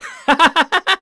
Zafir-Vox_Happy3_kr.wav